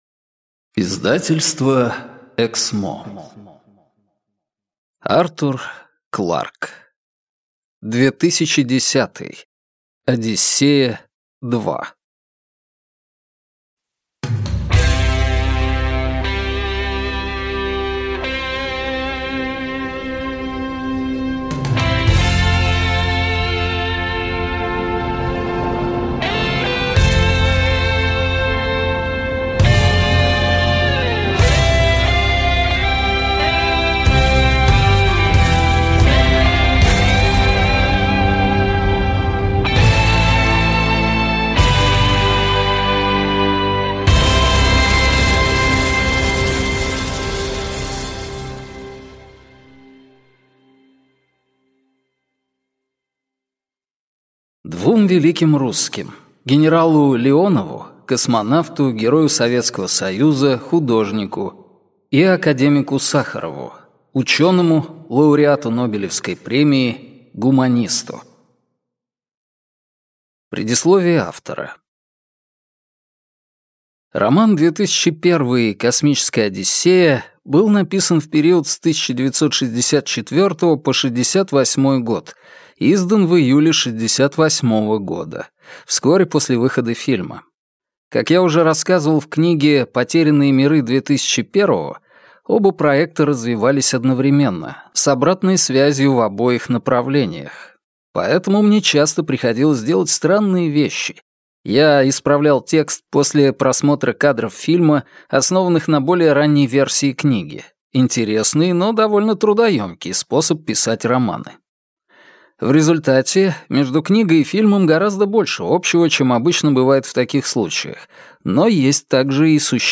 Аудиокнига 2010: Одиссея Два | Библиотека аудиокниг